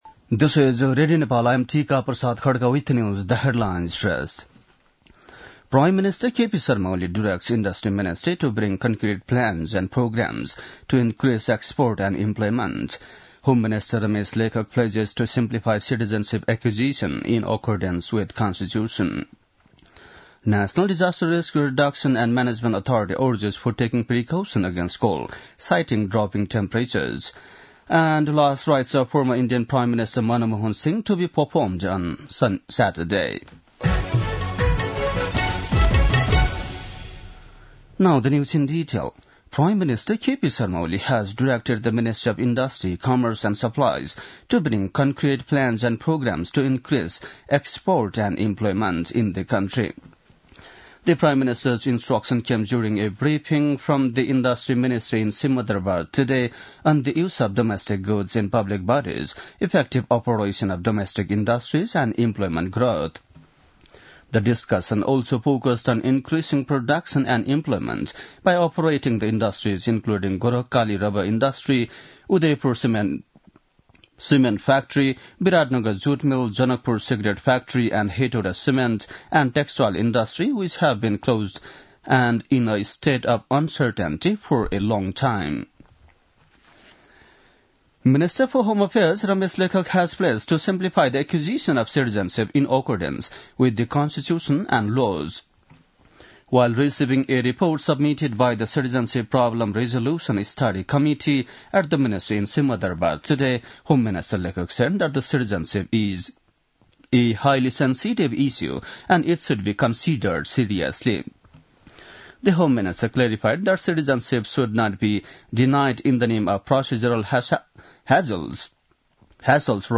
बेलुकी ८ बजेको अङ्ग्रेजी समाचार : १३ पुष , २०८१